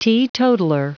Prononciation du mot teetotaler en anglais (fichier audio)
Prononciation du mot : teetotaler